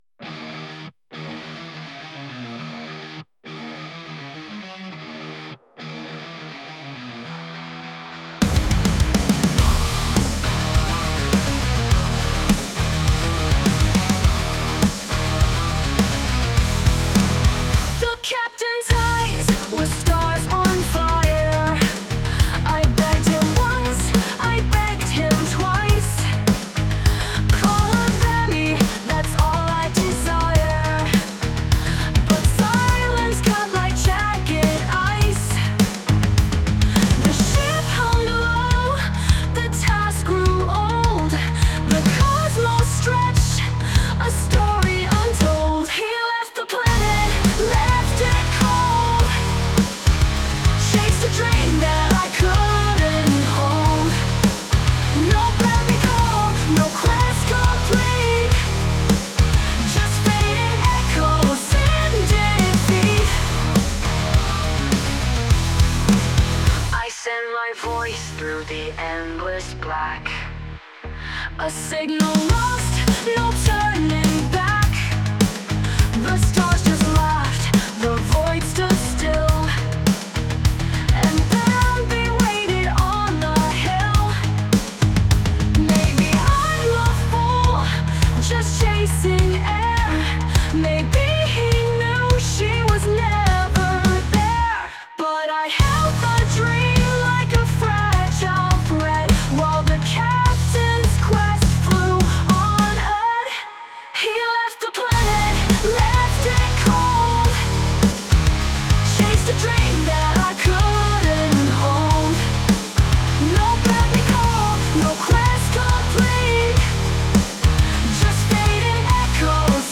Dans un élan d’inspiration (et d’auto-dérision), je décide de transformer cet incident en musique générée par IA.
Un peu triste, un peu grandiose. Un morceau aux accents spatiaux, presque mélancoliques, qui sonne comme une ballade galactique.